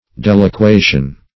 Deliquation \Del`i*qua"tion\, n.